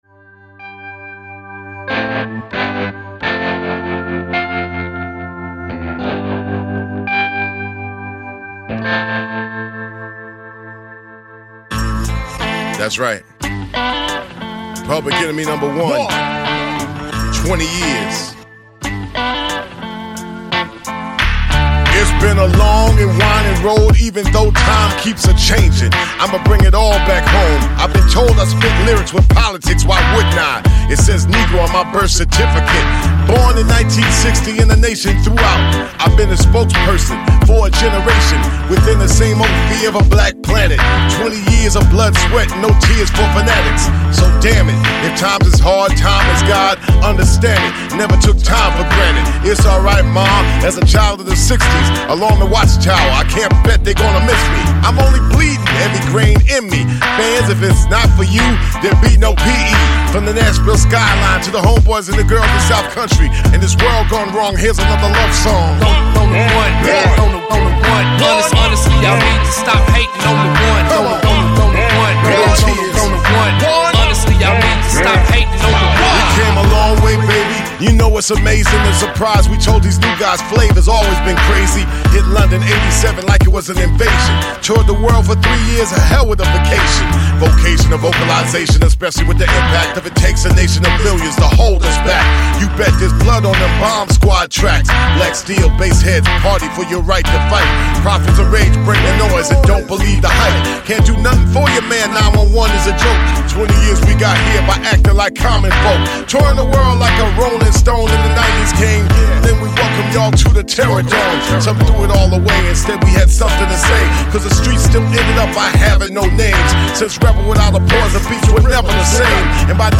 Жанр: Rap/Hip Hop